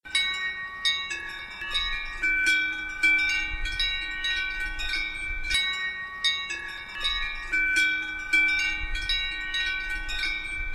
Dans cette page nous offrons des sonneries issues d’enregistrements de troupeaux.
Carlucet (Cantal)
Troupeau avec Varrone Premana casque
et Devouassoud Cairan et campane